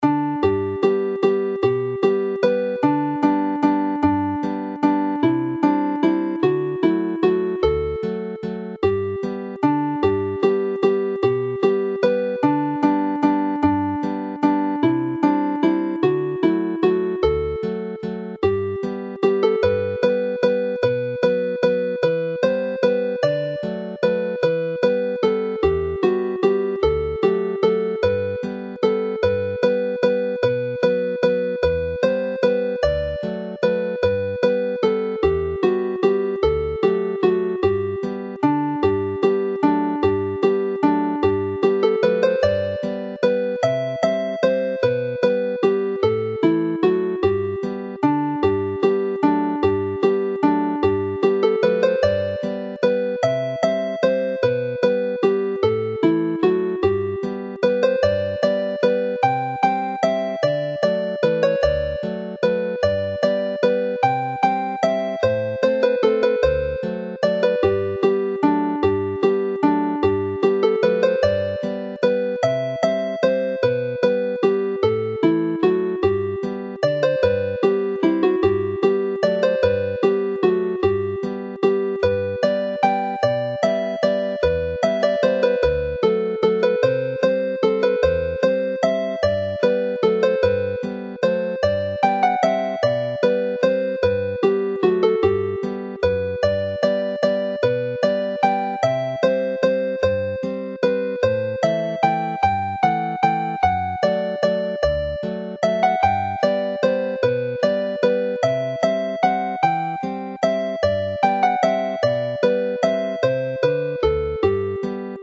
These three waltzes, all in G major